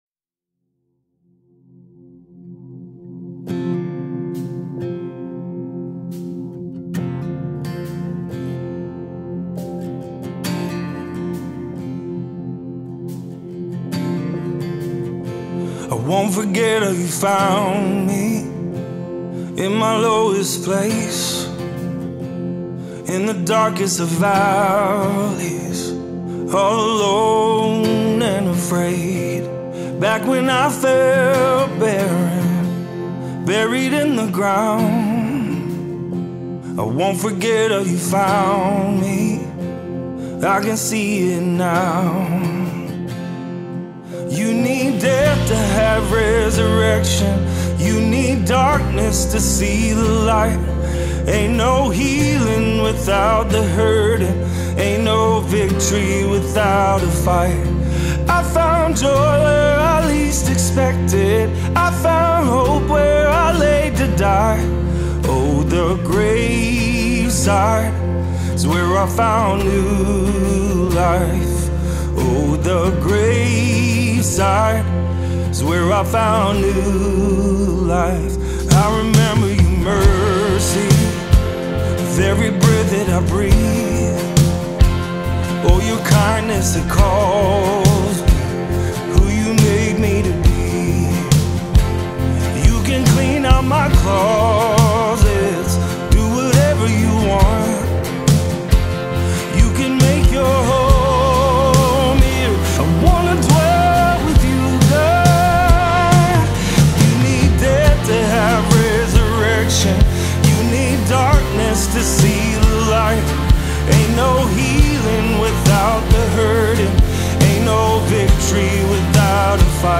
2025 single